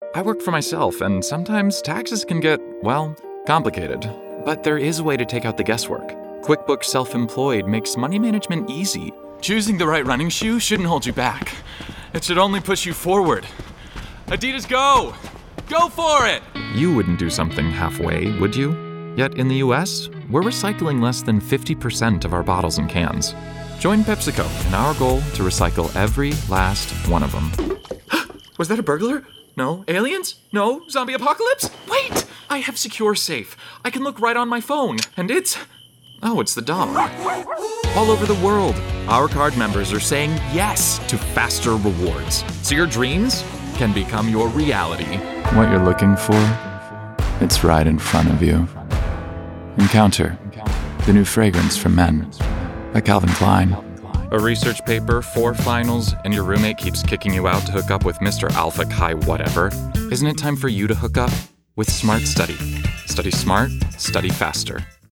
Voiceover : Commercial : Men
Commercial Demo